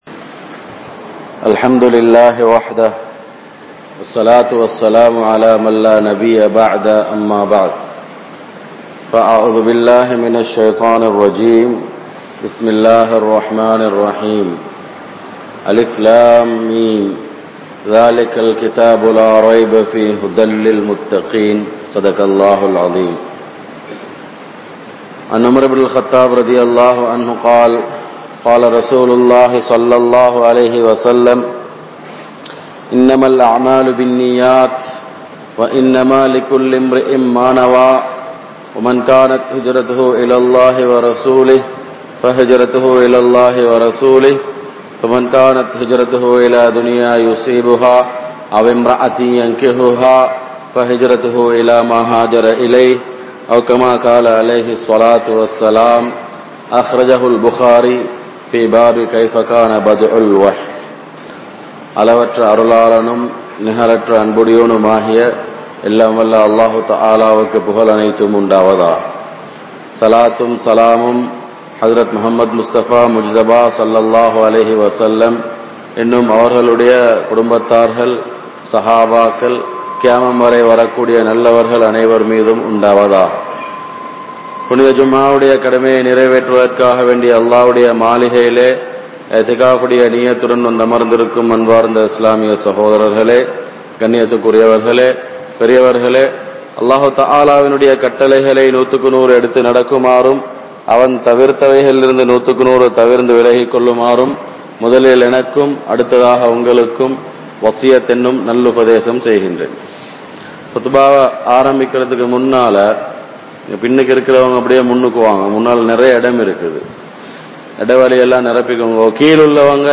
Matravarhalai Mathiungal (மற்றவர்களை மதியுங்கள்) | Audio Bayans | All Ceylon Muslim Youth Community | Addalaichenai